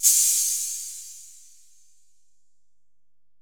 808LP46OHH.wav